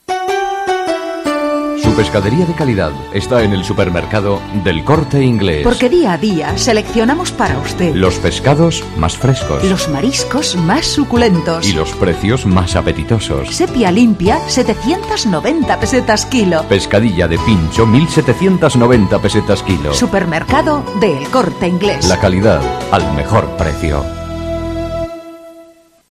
Anuncios sobre la fiesta de la moda, servicios de El Corte Inglés, supermercados, ropa de baño... narrados con voces sugerentes y aún en pesetas, que nos recuerdan cómo la herencia del pasado puede servirnos para alumbrar nuevas estrategias en el futuro.
Bajo la premisa de "la calidad al mejor precio", dos voces se alternan en este corte. para presentar el precio de la sepia limpia o de la pescadilla.
Anuncio de pescadería El Corte Inglés